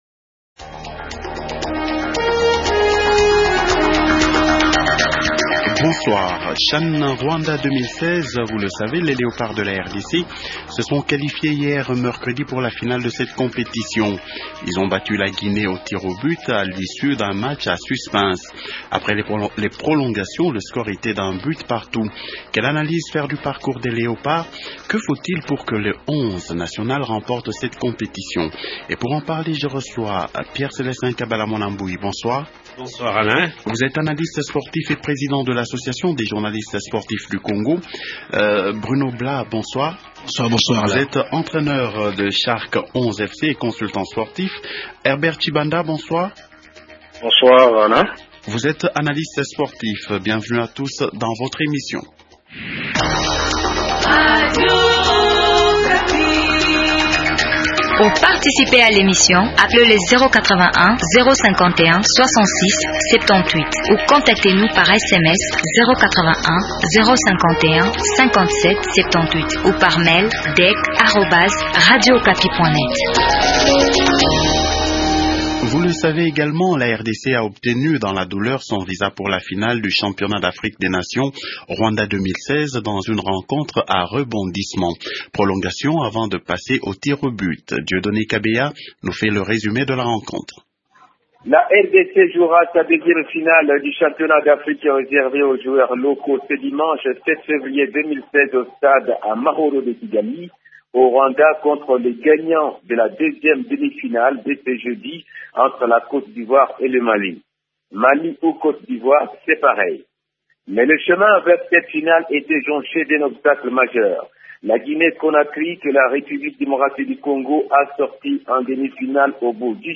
Les débatteurs de ce soir sont :